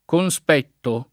conspetto [ kon S p $ tto ]